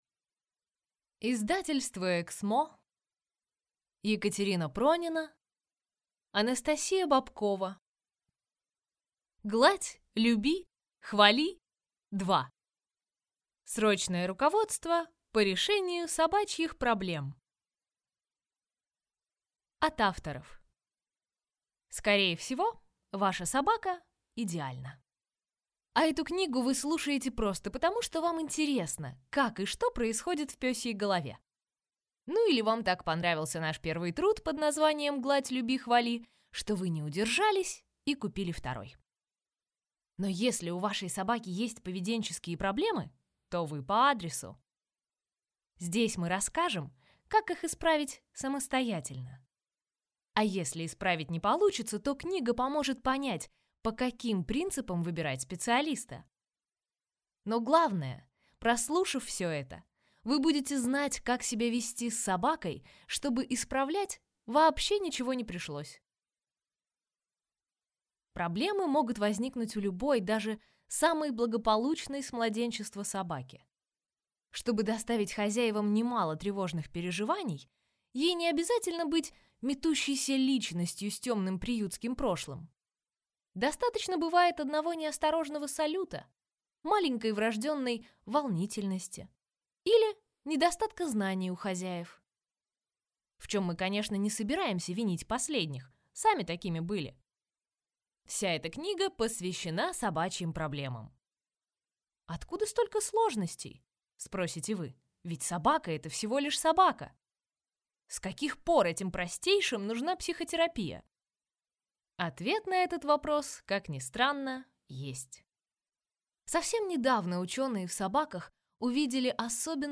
Аудиокнига Гладь, люби, хвали 2: срочное руководство по решению собачьих проблем | Библиотека аудиокниг